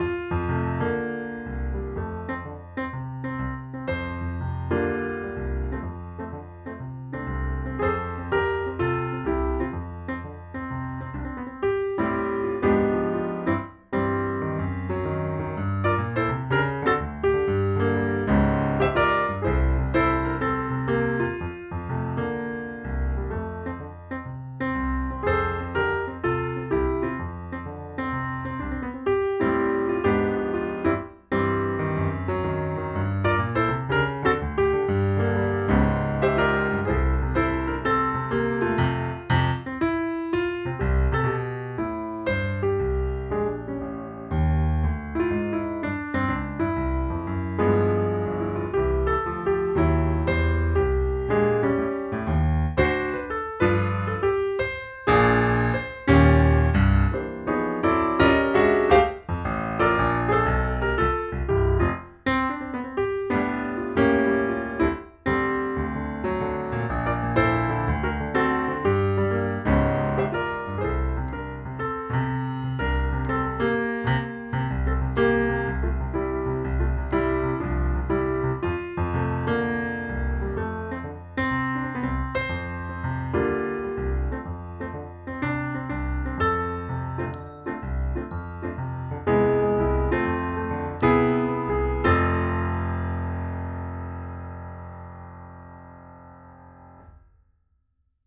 This is very beautiful, so relaxing.